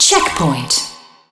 checkpoint.wav